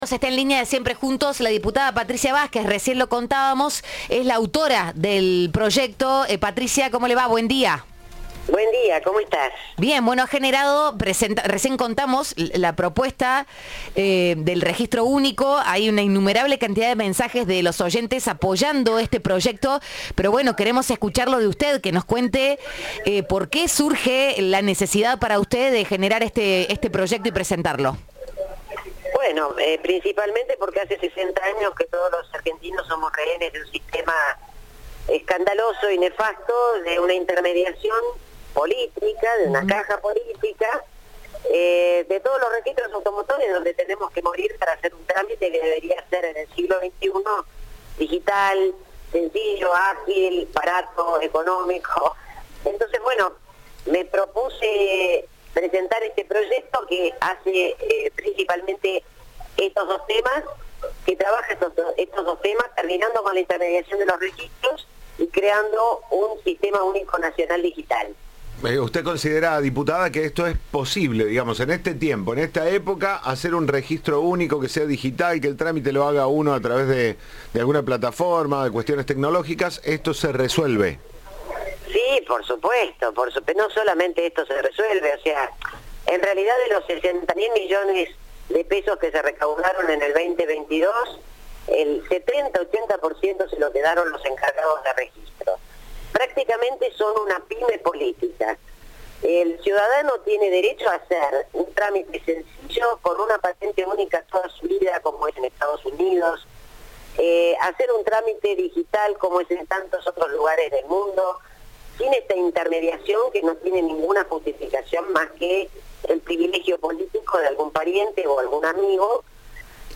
Se trata de Patricia Vásquez, legisladora de Juntos por el Cambio, quien le dijo a Cadena 3 que quiere "eliminar con la burocracia y el curro de la política".
Entrevista de "Siempre Juntos".